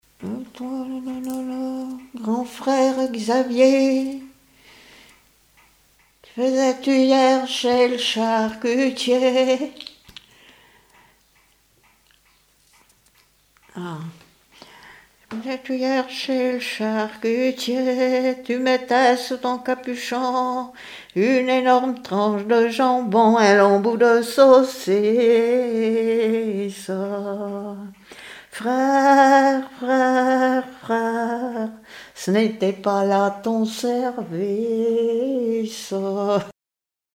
Genre strophique
Enquête Arexcpo en Vendée-Pays Sud-Vendée
Pièce musicale inédite